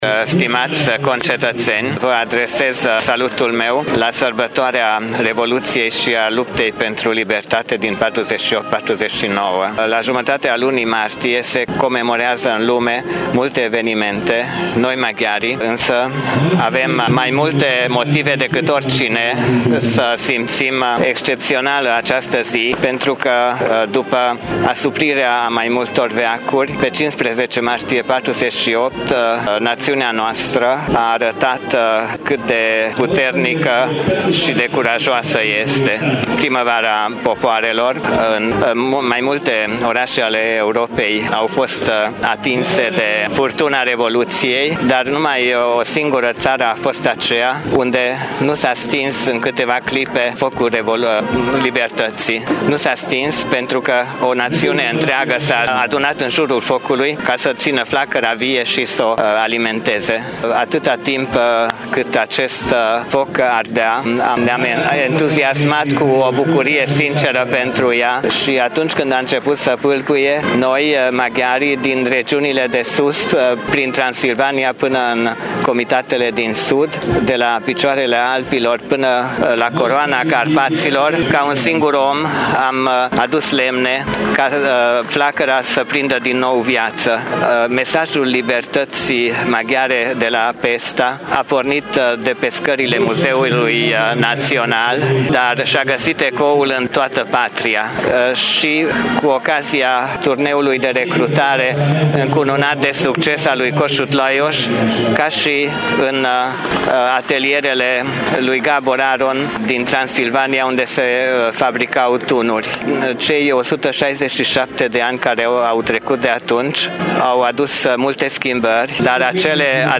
Traducerea scrisorii pentru Radio Tîrgu-Mureș a fost asigurată de consilierul local , Haller Bela: